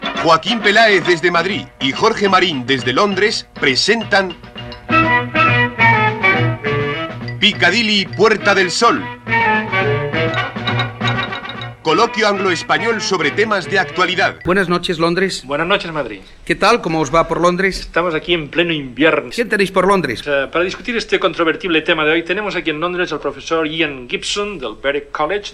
Careta del programa, salutació inicial i presentació de l'invitat
Entreteniment